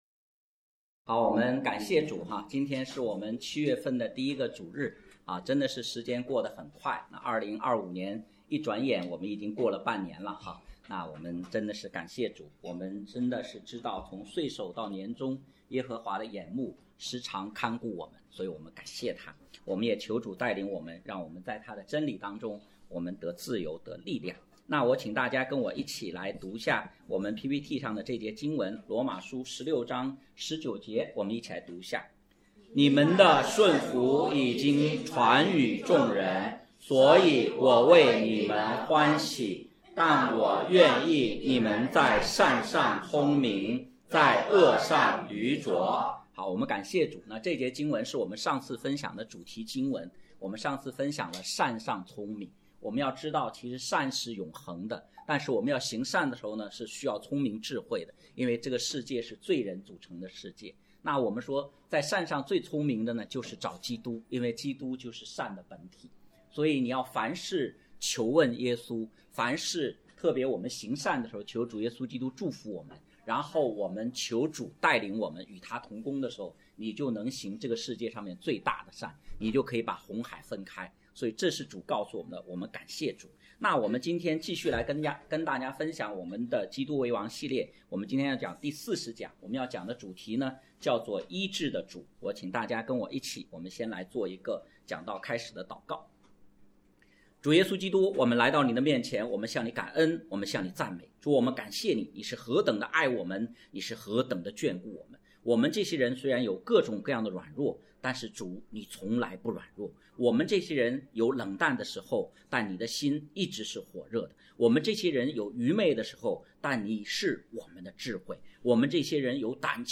讲道录音